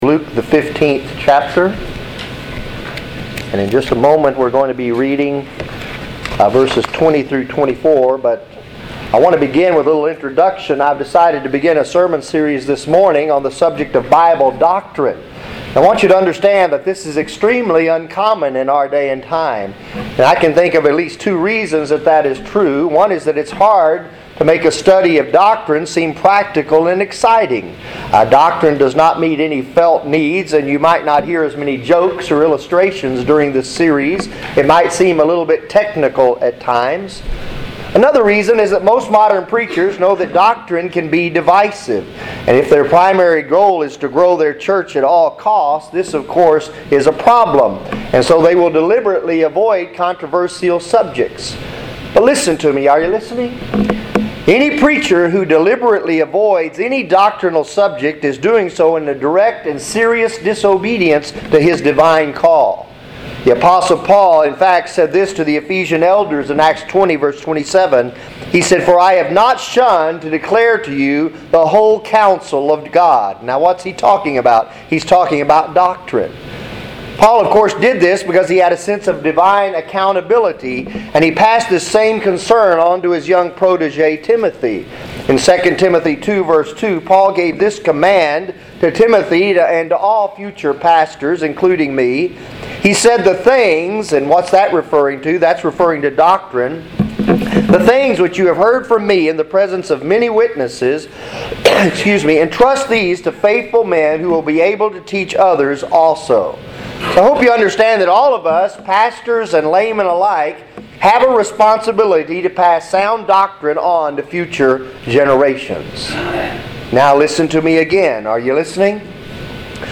Sermons | Providential Baptist Church